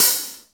Index of /90_sSampleCDs/Roland L-CDX-01/KIT_Drum Kits 2/KIT_Dry Kit
HAT REAL H08.wav